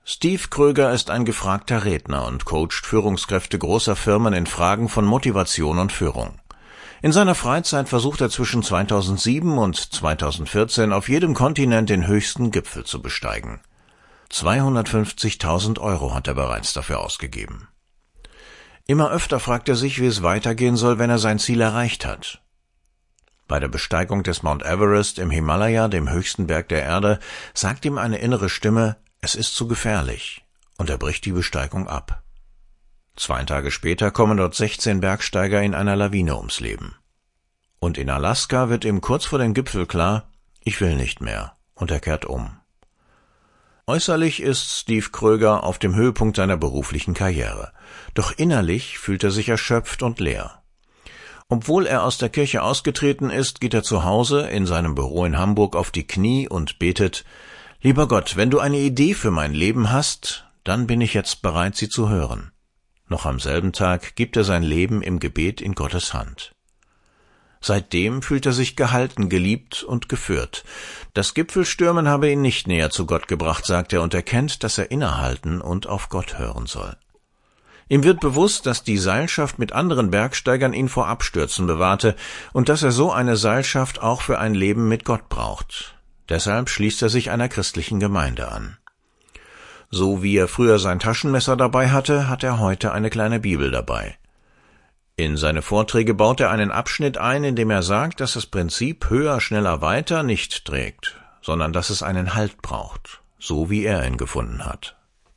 Diesen Radiobeitrag